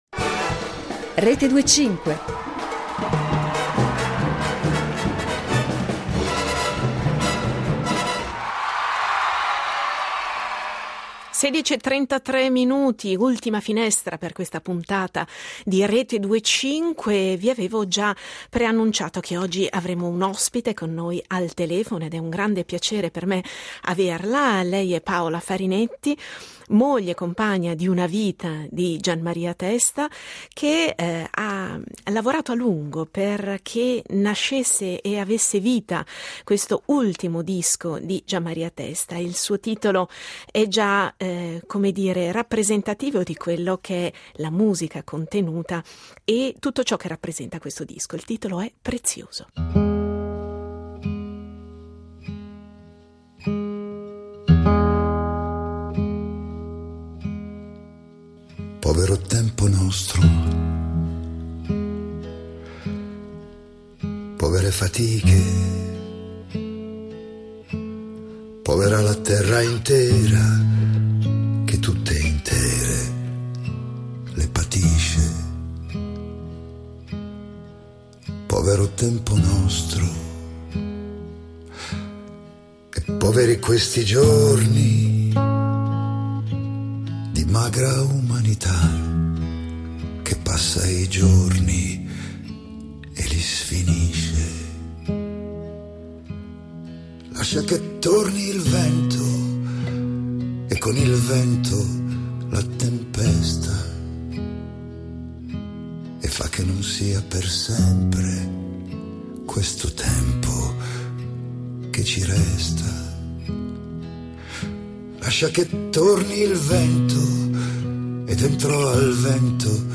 Incontro